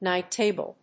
アクセントníght tàble